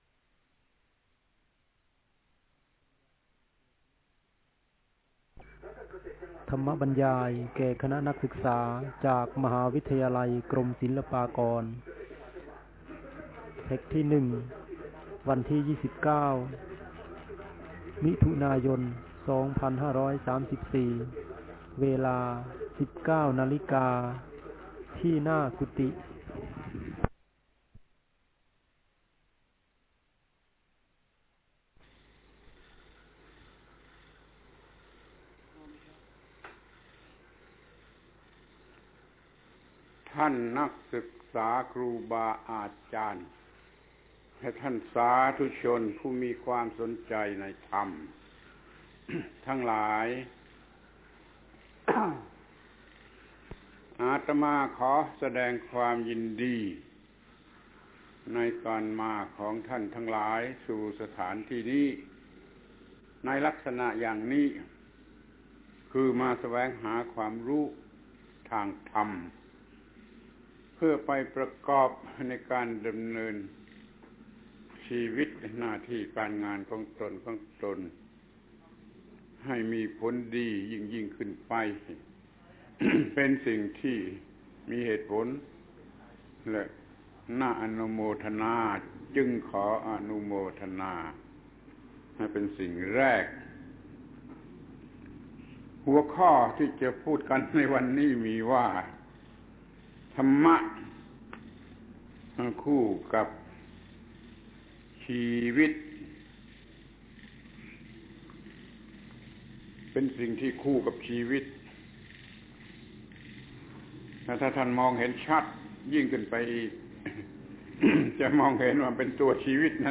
พระธรรมโกศาจารย์ (พุทธทาสภิกขุ) - บรรยายแก่คณะนักศึกษาจากมหาวิทยาลัยศิลปากร ธรรมะคู่กับชีวิต